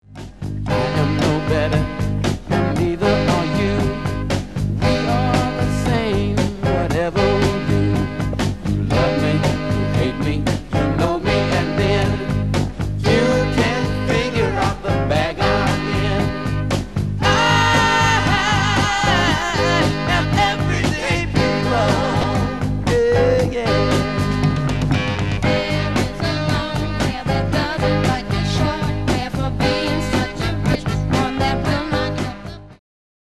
guitar, background vocals
lead and background vocals
drums, background vocals
saxophone, background vocals
trumpet, background vocals
bass guitar, background vocals